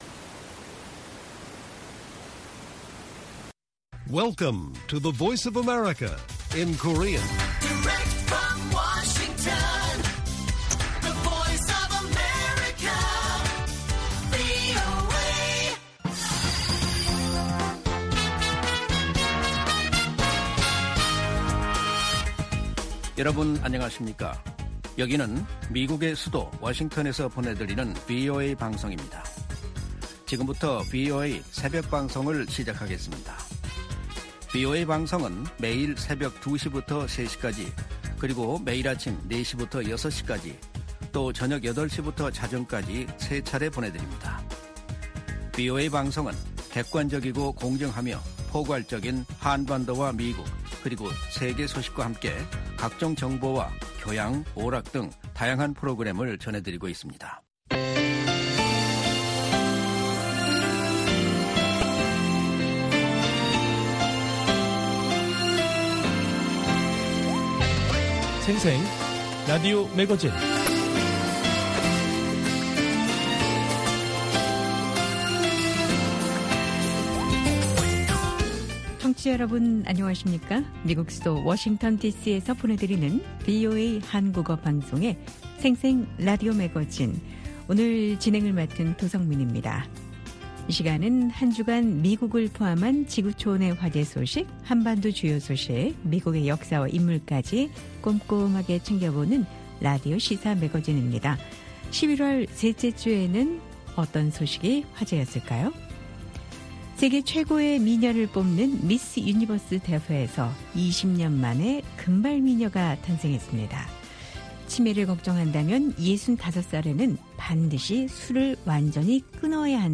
VOA 한국어 방송의 월요일 새벽 방송입니다. 한반도 시간 오전 2:00 부터 3:00 까지 방송됩니다.